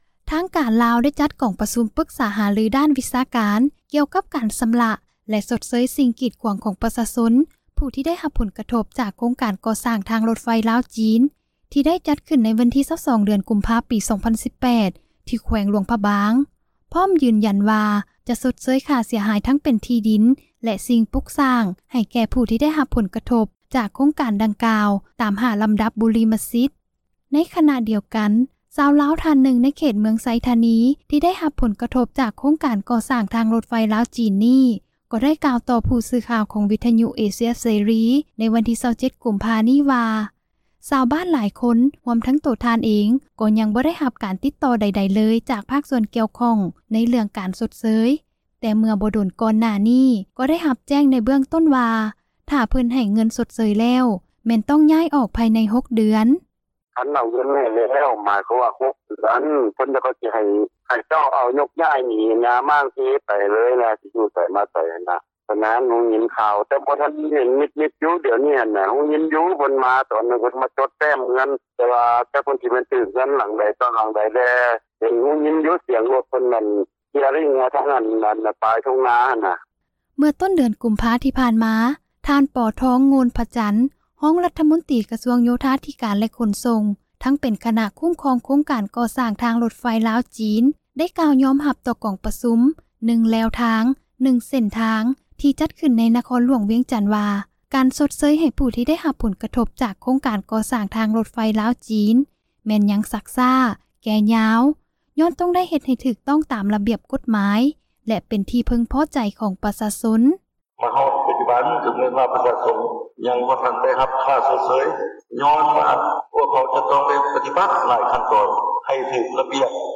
ໃນຂນະດຽວກັນ, ຊາວລາວທ່ານນຶ່ງ ໃນເຂດເມືອງໄຊທານີ ທີ່ໄດ້ຮັບຜົລກະທົບ ຈາກໂຄງການກໍ່ສ້າງ ທາງຣົດໄຟ ລາວ-ຈີນ ນີ້ ກໍໄດ້ກ່າວຕໍ່ ຜູ້ສື່ຂ່າວເອເຊັຽເສຣີ ໃນວັນທີ 27 ກຸມພາ 2018 ນີ້ວ່າ ຊາວບ້ານຫຼາຍຄົນ ຮວມທັງທ່ານເອງ ກໍຍັງບໍ່ໄດ້ຮັບການ ຕິດຕໍ່ໃດໆເລີຍ ຈາກ ພາກສ່ວນກ່ຽວຂ້ອງ ໃນເຣື້ອງການຊົດເຊີຍ, ແຕ່ເມື່ອບໍ່ດົນ ກ່ອນໜ້ານີ້ ກໍໄດ້ຮັບແຈ້ງ ໃນເບື້ອງຕົ້ນວ່າ ຖ້າເພິ່ນໃຫ້ເງິນຊົດເຊີຍ ແລ້ວແມ່ນ ຕ້ອງຍ້າຍອອກ ພາຍໃນ 6 ເດືອນ.